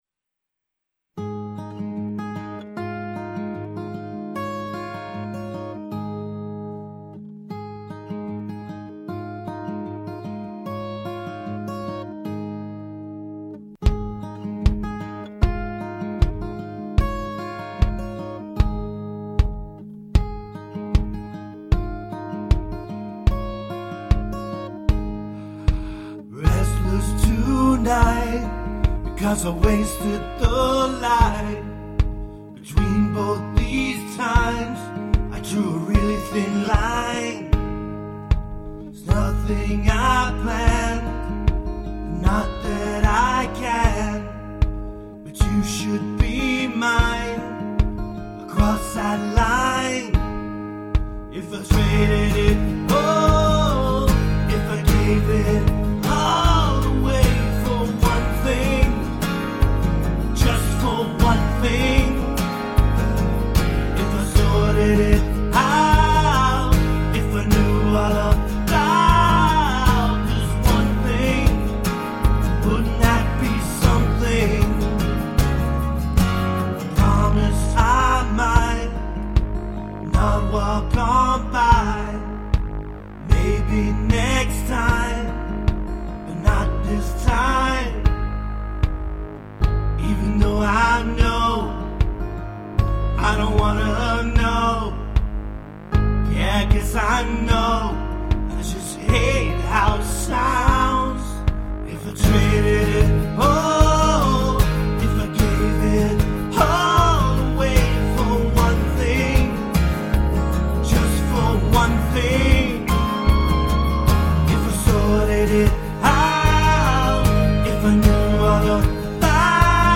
Vocals
I also mixed and mastered the instruments.